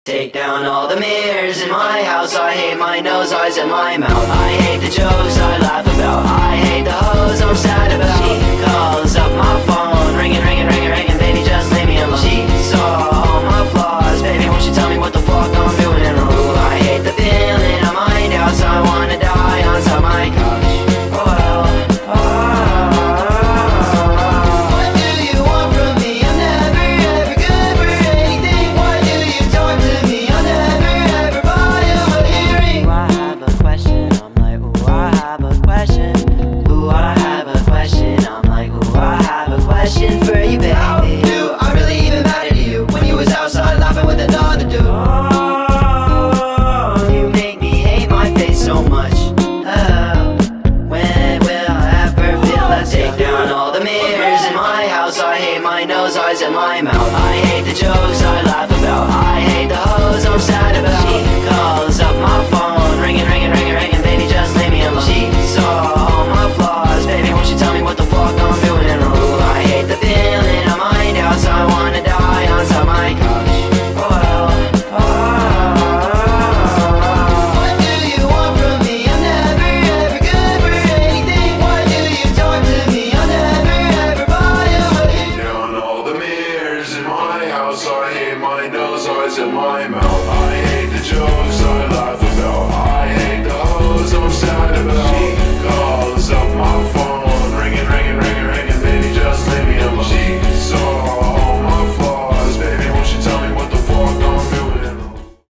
ویژگی اصلی این موزیک، فضای تاریک و درون‌گرایانه آن است.